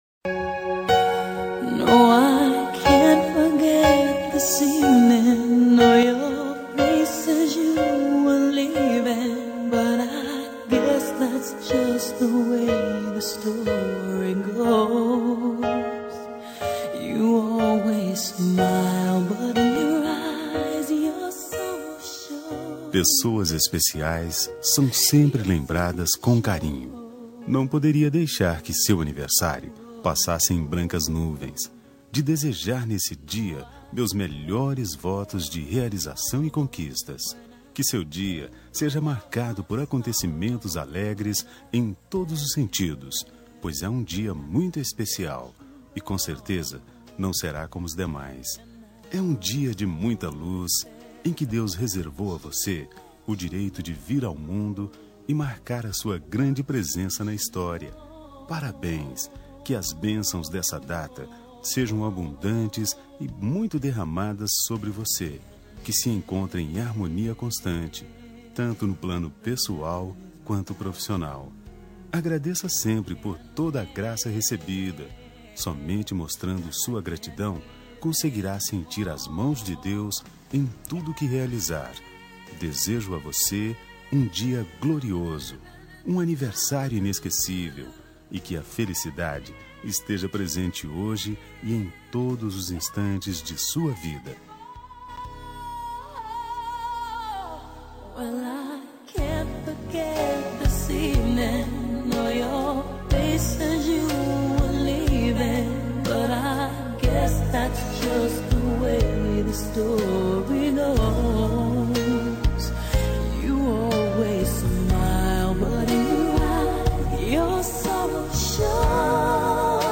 Aniversário de Ficante – Voz Masculina – Cód: 8881
aniv-ficante-masc-8881.m4a